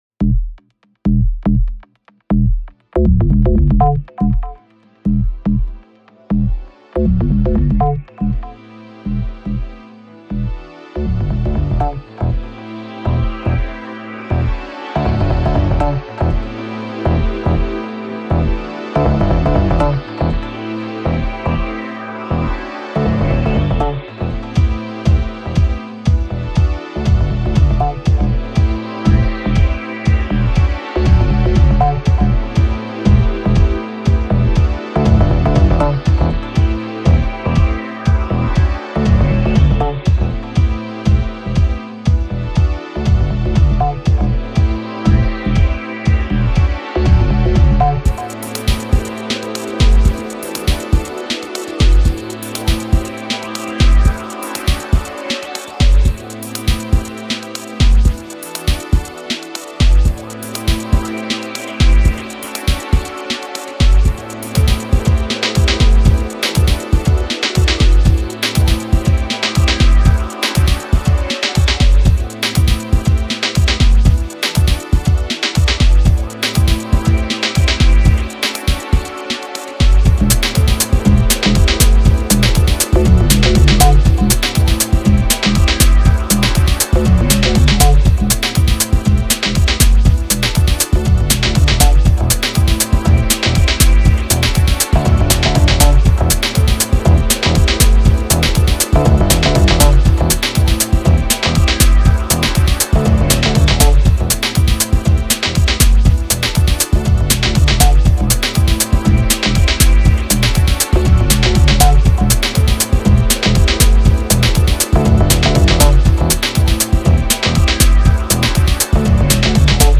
Edm, Retro, Synthwave and House music.…